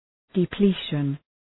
Προφορά
{dı’pli:ʃən}